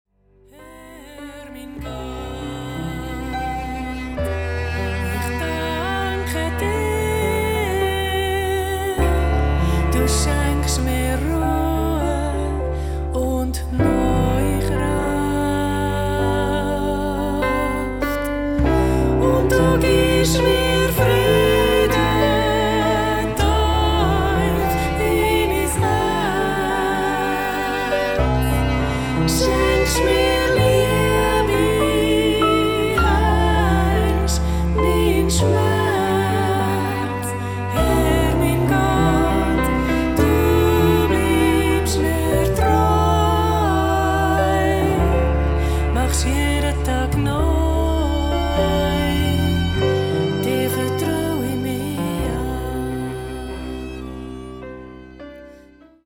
Ihre sanften Lieder in 432Hz
verbreiten eine Atmosphäre von Frieden und Zuversicht